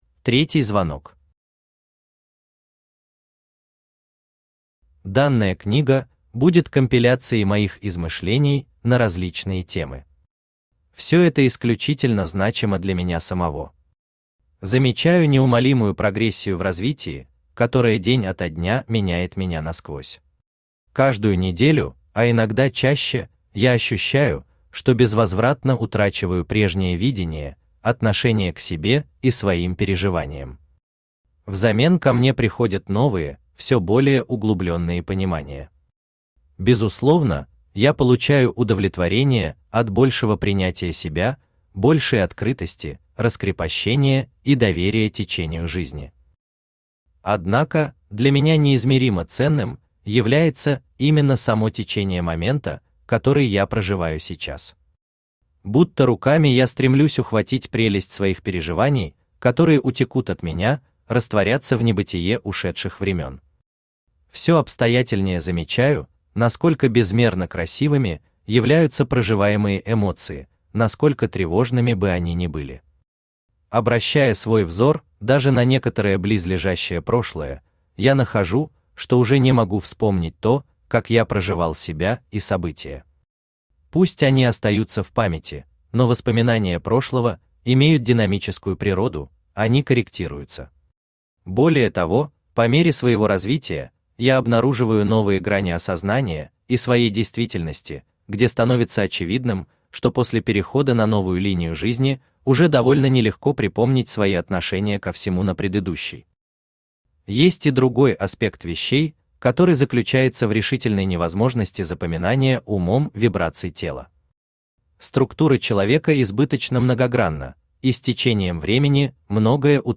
Аудиокнига Сентенции Любви | Библиотека аудиокниг
Aудиокнига Сентенции Любви Автор Александр Алексеевич Ананьев Читает аудиокнигу Авточтец ЛитРес.